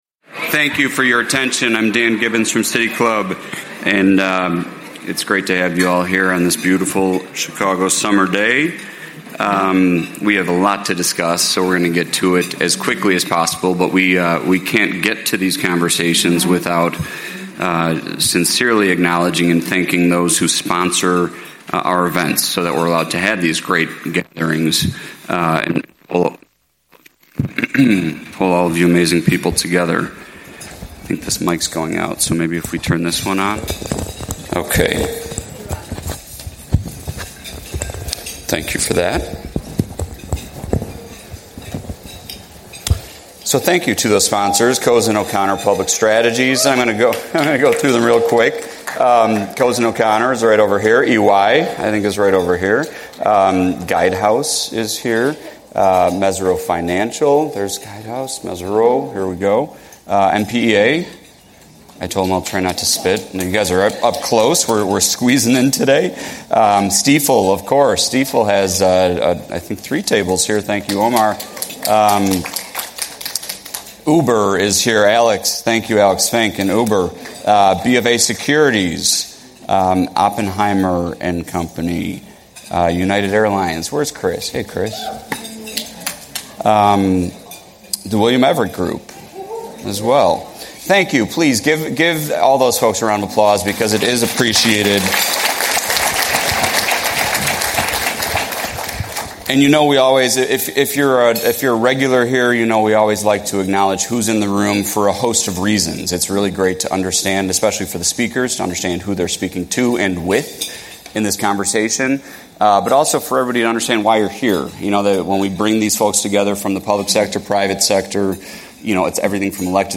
As the City of Chicago heads into a new budget season, conversations about revenue, spending, and long-term financial sustainability are once again front and center. This City Club program offers a timely opportunity to level-set: What powers does the City actually have?